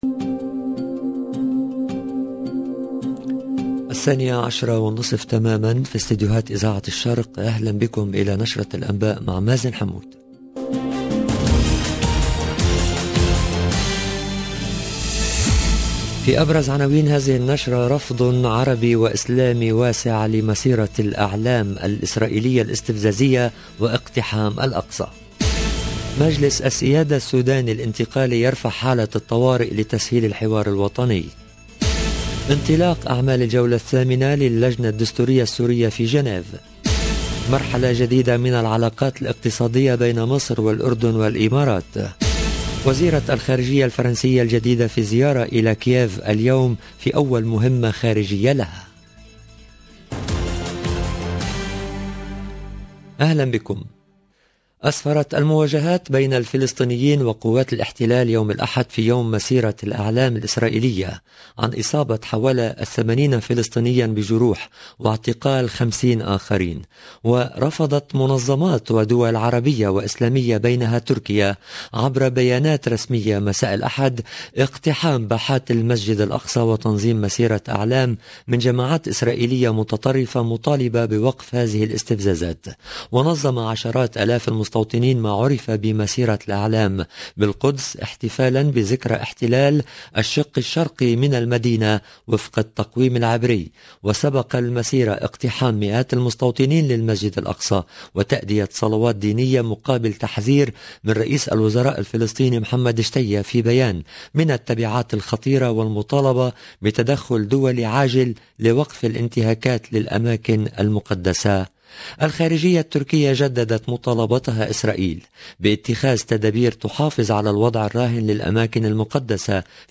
LE JOURNAL EN LANGUE ARABE DE MIDI 30 DU 30/05/22